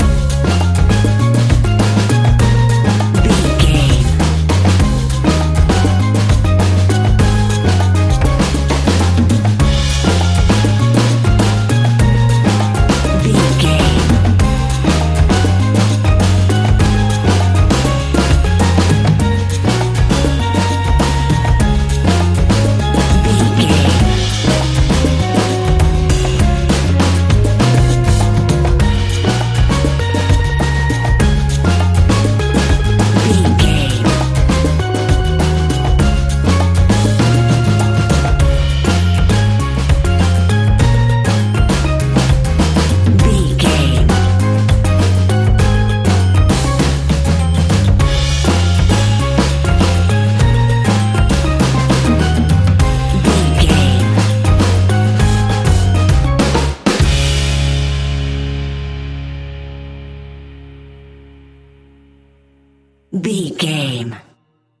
Ionian/Major
D♭
steelpan
calypso music
drums
percussion
bass
brass
guitar